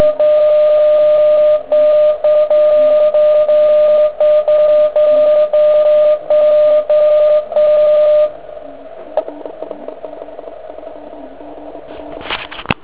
Na�e vys�la�e typu RSI jsme si pomoc� diferenci�ln�ho kl��ov�n� "ladili" na zvoniv� t�nky.
Podle vlastn� zku�enosti mohu ��ci, �e s takov�m sign�lem by asi stanice z Kalifornie t�ko d�lal.